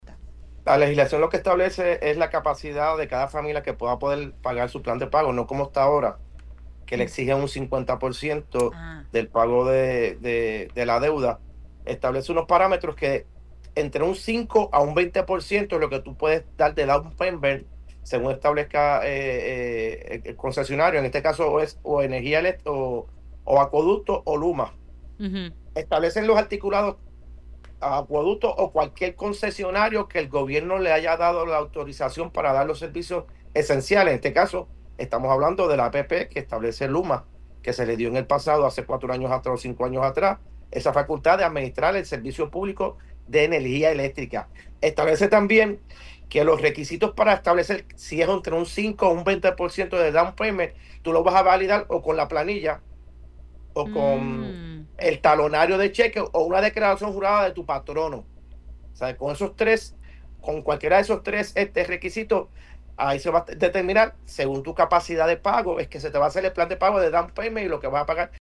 O sea, con esos tres, con cualquiera de esos tres requisitos, ahí se va a determinar según tu capacidad de pago es que se te va a hacer el plan de pago de down payment y lo que vas a pagar mensualmente”, detalló el representante en Pega’os en la Mañana.